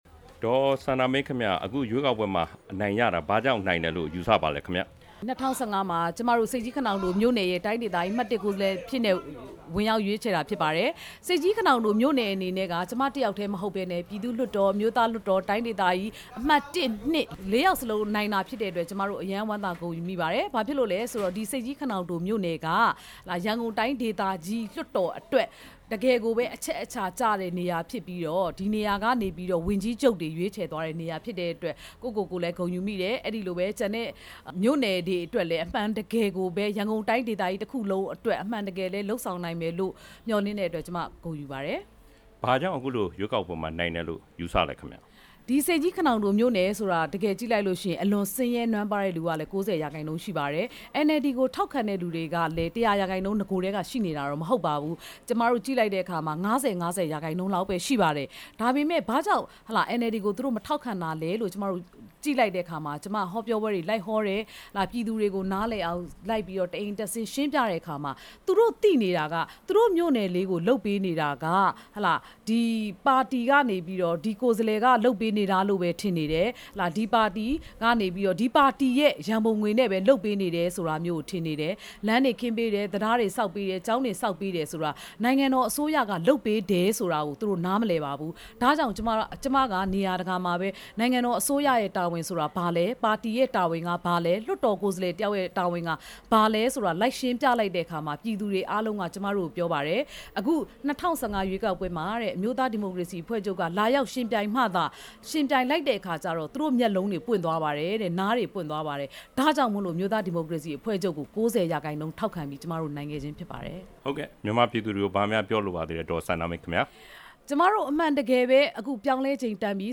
ရွေးကောက်ပွဲအနိုင်ရမှုနဲ့ပတ်သက်ပြီး ဒေါ်စန္ဒာမင်းနဲ့ မေးမြန်းချက်